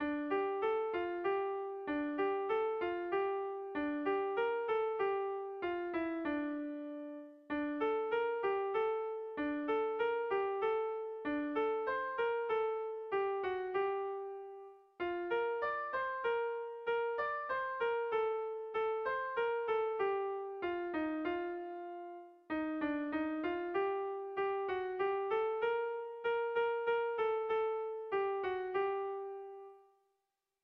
Sentimenduzkoa
Zortziko handia (hg) / Lau puntuko handia (ip)